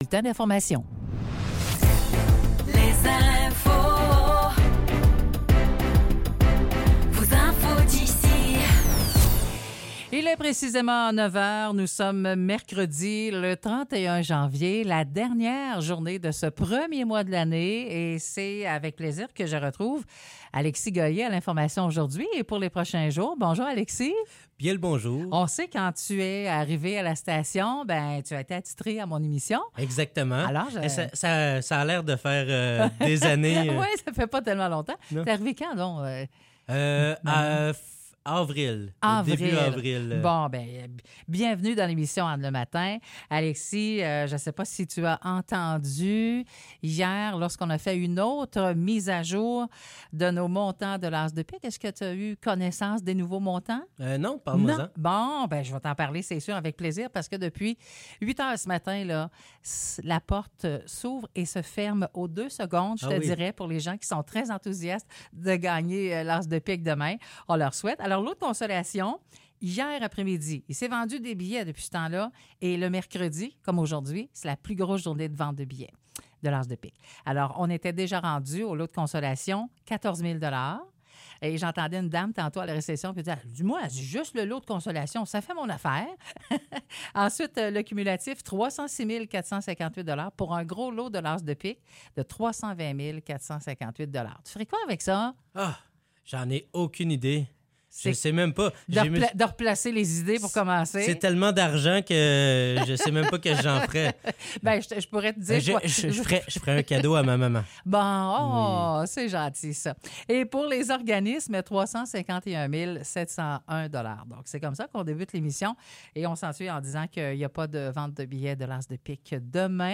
Nouvelles locales - 31 janvier 2024 - 9 h